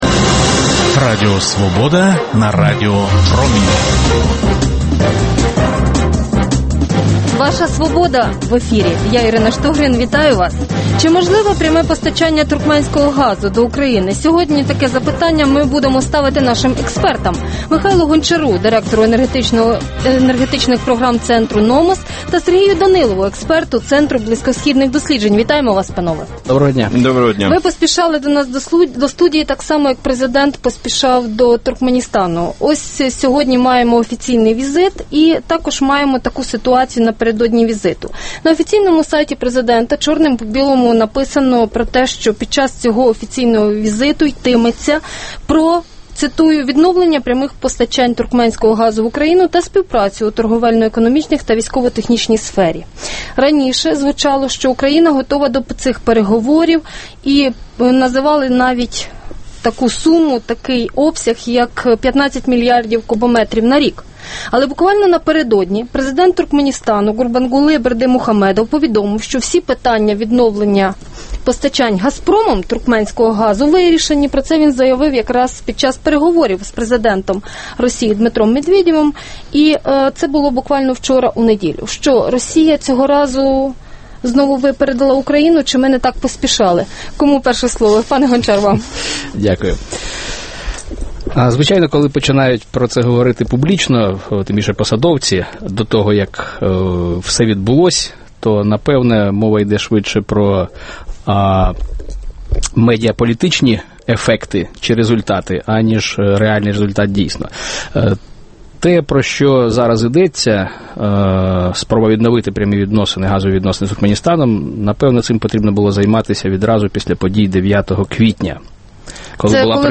Дискусія про головну подію дня